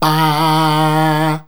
BAAAAAH A#.wav